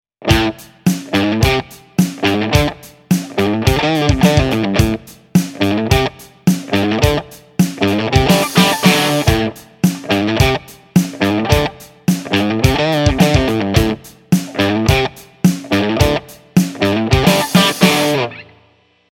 LRT-008 Sixteenth Triplets Riff
lrt008sixteenthtripletsriff.mp3